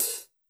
BAL Open Hat.wav